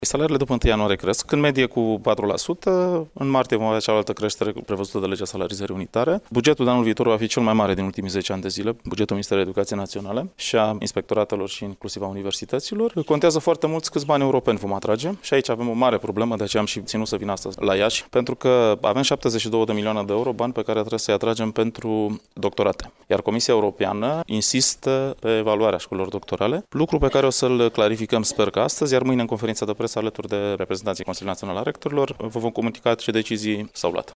Creşterile salariale pentru angajaţii Ministerului Educaţiei vor fi de aproximativ 4%, de la 1 ianuarie 2018, a dat asigurări, astăzi, la Iaşi, ministrul de resort Liviu Pop.
Ministrul Educaţiei, Liviu Pop, şi secretarul de stat, Gigel Paraschiv, au participat, astăzi, la întâlnirea Consiliului Naţional al Rectorilor care s-a desfăşurat la Iaşi.